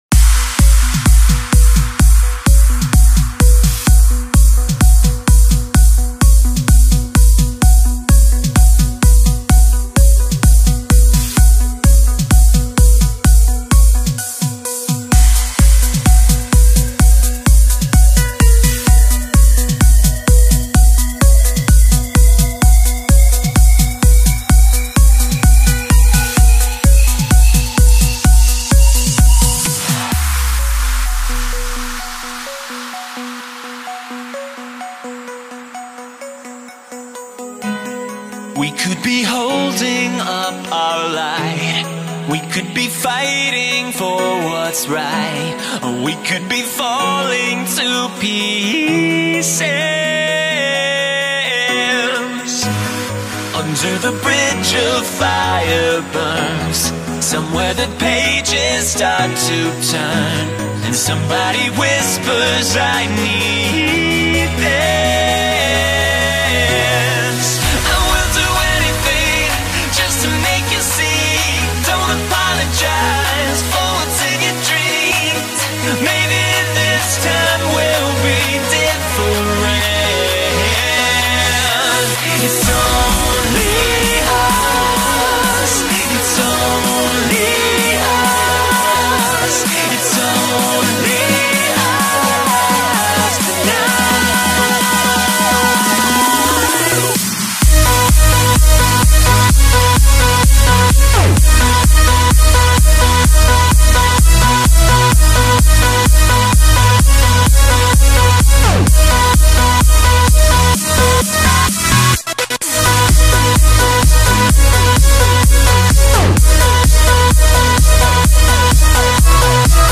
Hardstyle, Euphoric, Happy, Hopeful, Energetic